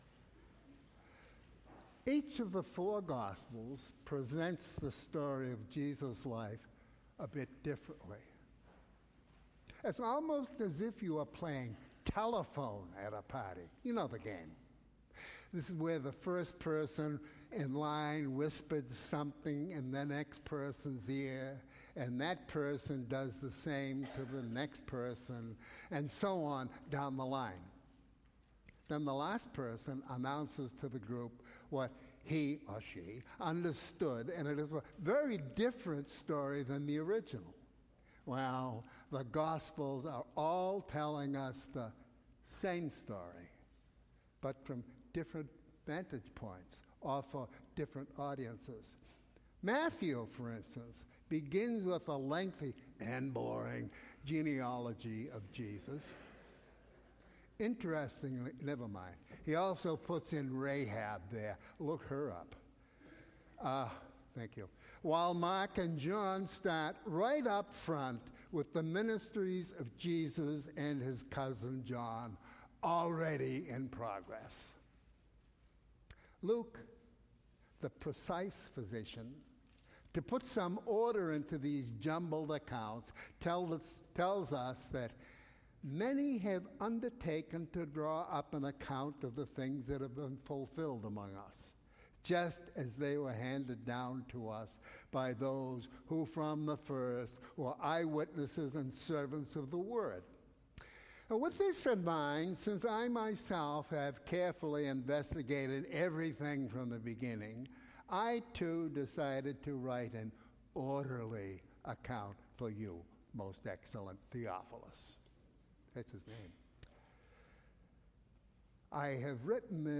Sermon- December 23, 2018 – All Saints' Episcopal Church